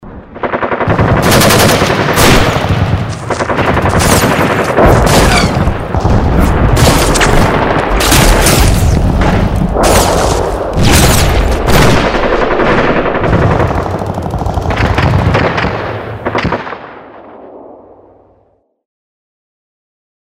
جلوه های صوتی
دانلود صدای تفنگ 3 از ساعد نیوز با لینک مستقیم و کیفیت بالا